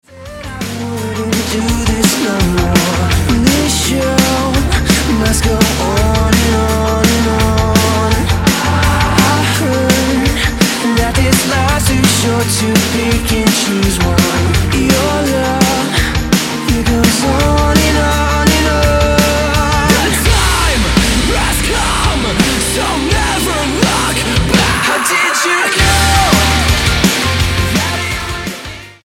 Californian metal band
Style: Hard Music